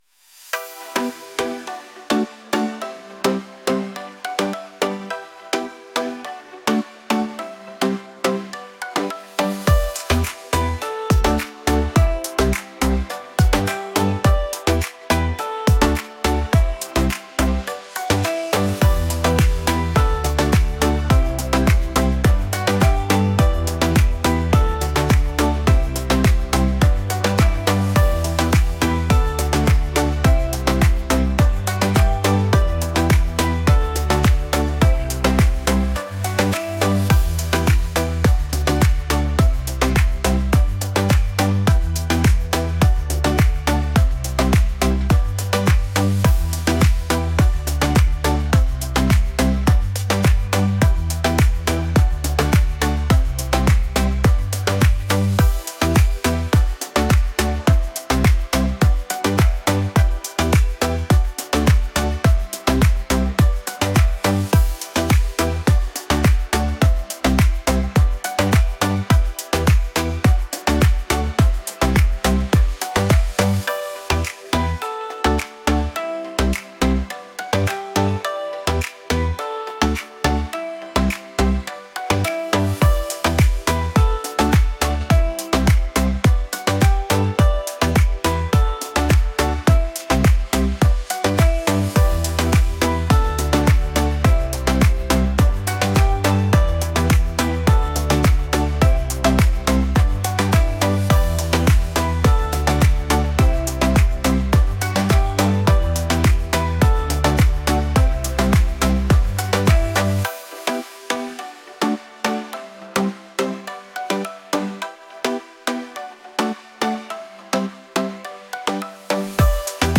pop | romantic